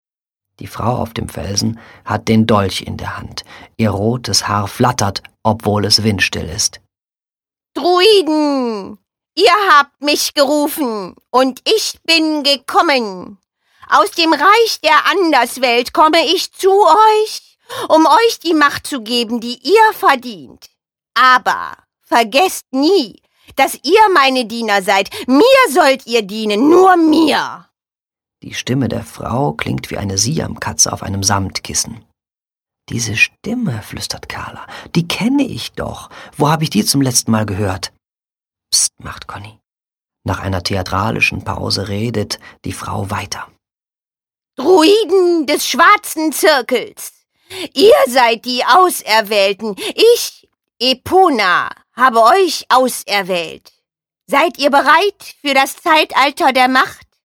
✔ tiptoi® Hörbuch ab 7 Jahren ✔ Jetzt online herunterladen!